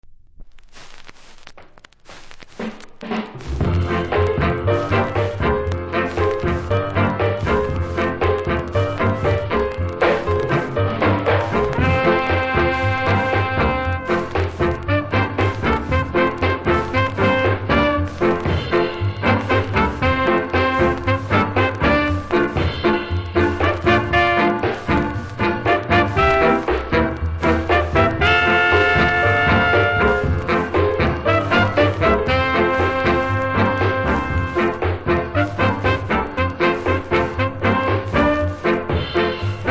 プレス起因のノイズがありますので試聴で確認下さい。